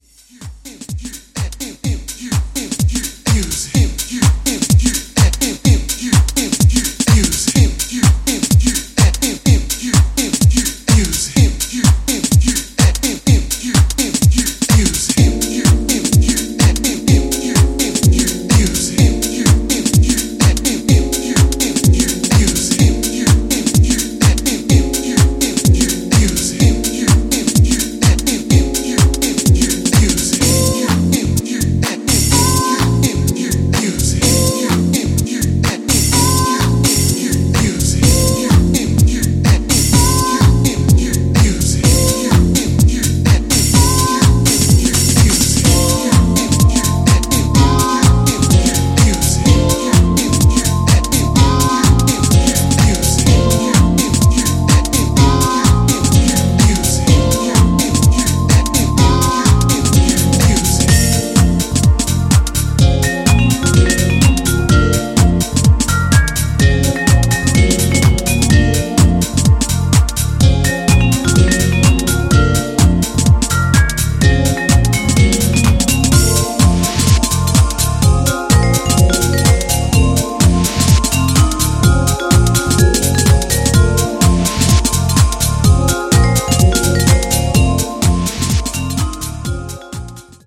2. > HOUSE・TECHNO
ジャンル(スタイル) HOUSE / DISCO